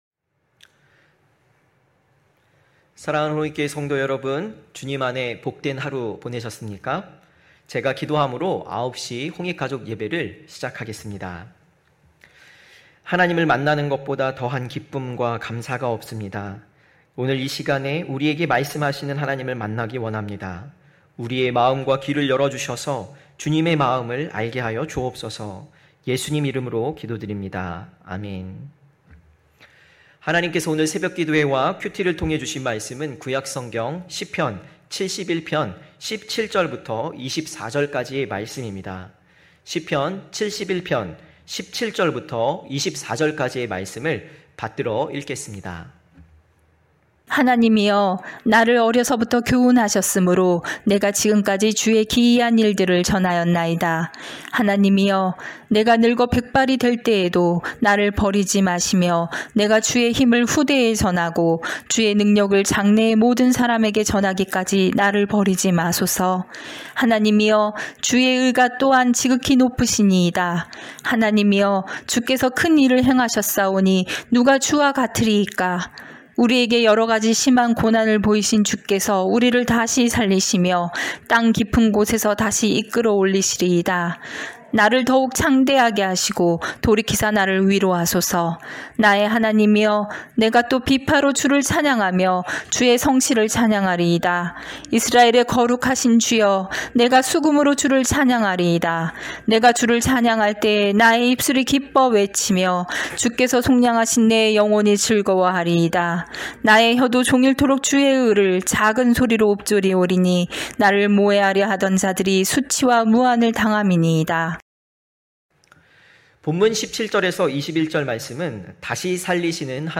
9시홍익가족예배(8월30일).mp3